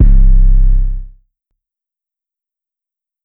808 (For Real).wav